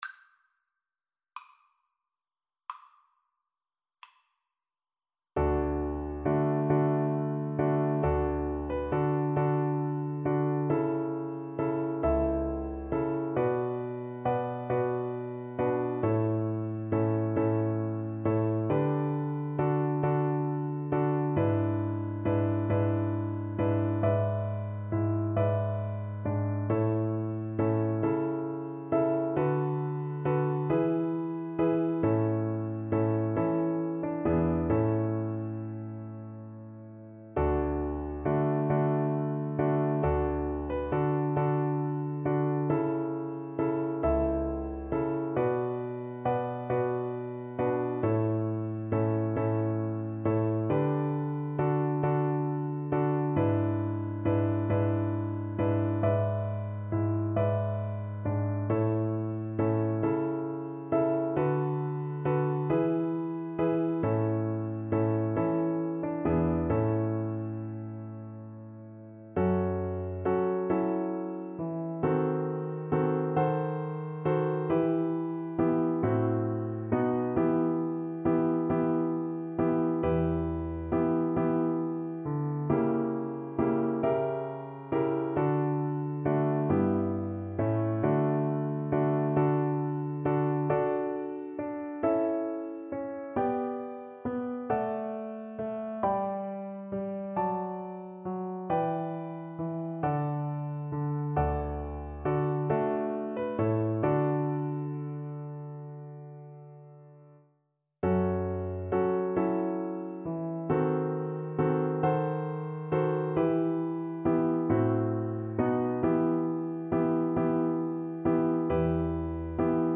D major (Sounding Pitch) (View more D major Music for Flute )
12/8 (View more 12/8 Music)
II: Larghetto cantabile .=45
Classical (View more Classical Flute Music)